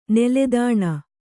♪ neledāṇa